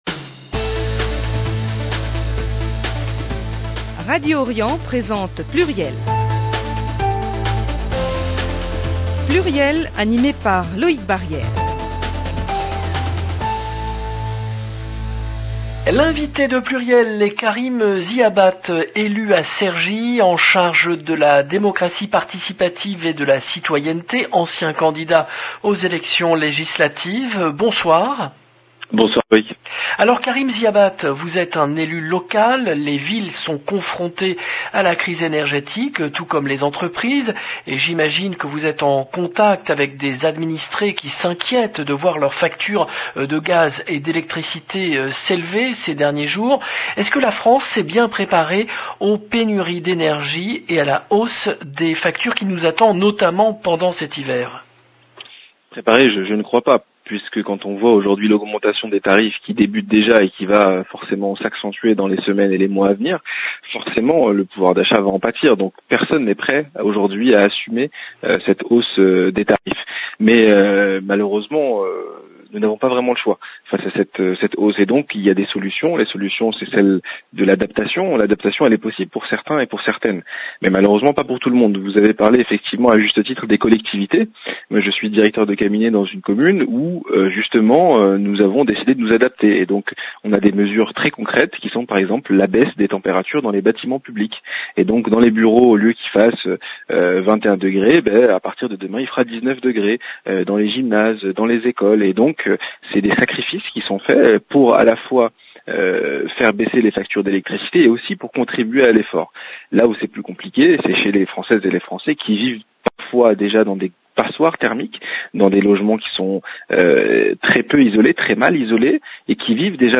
L’invité de PLURIEL est Karim Ziabat , élu de gauche à Cergy, en charge de la démocratie participative et de la citoyenneté, ancien candidat aux élections législatives